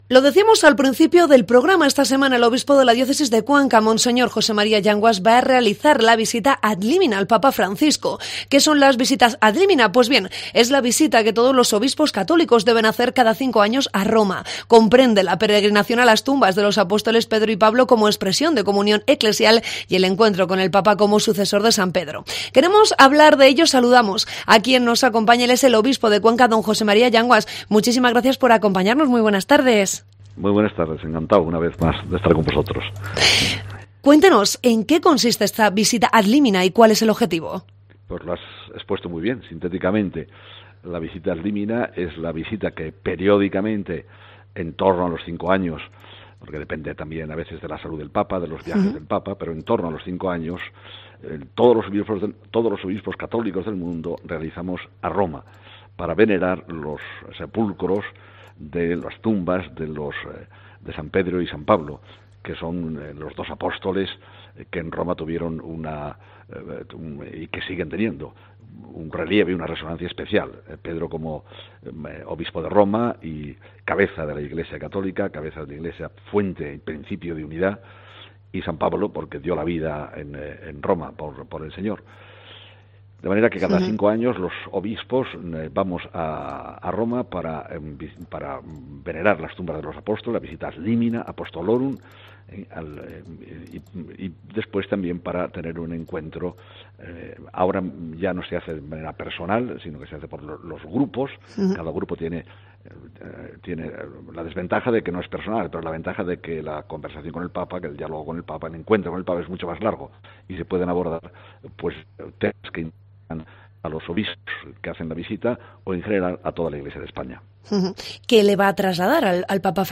Entrevista al Obispo de Cuenca, José María Yanguas